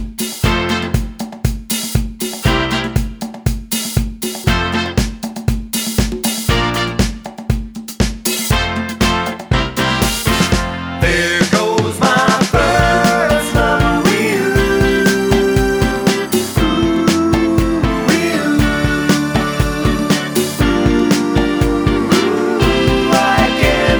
Soul / Motown